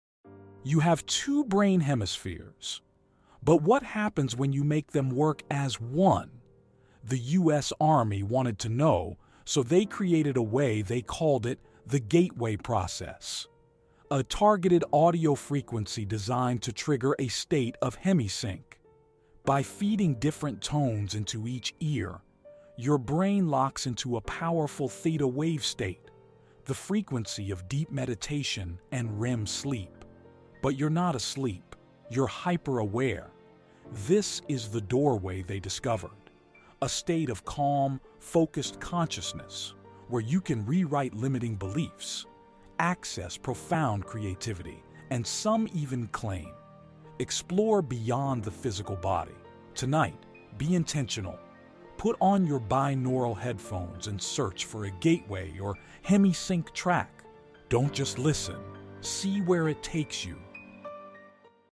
You'll need headphones for the binaural frequencies to work their magic.